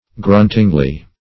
gruntingly - definition of gruntingly - synonyms, pronunciation, spelling from Free Dictionary Search Result for " gruntingly" : The Collaborative International Dictionary of English v.0.48: Gruntingly \Grunt"ing*ly\, adv.